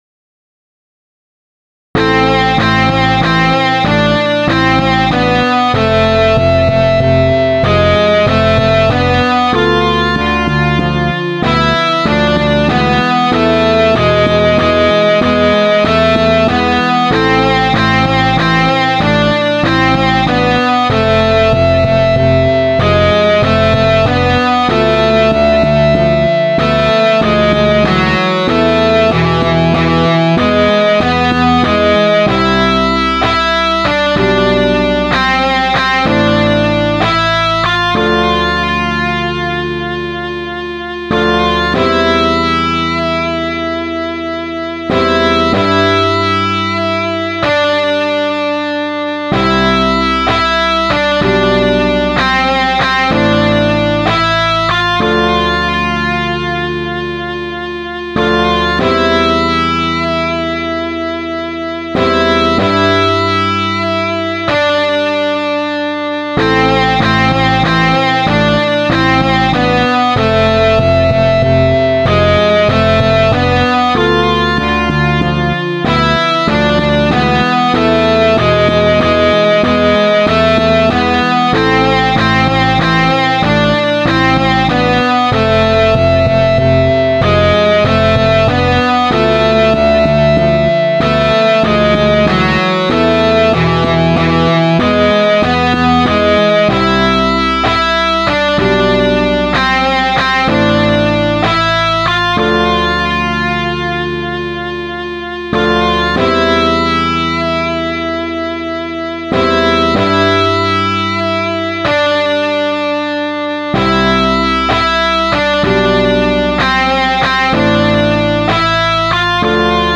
Now-BPM-95-MP3.mp3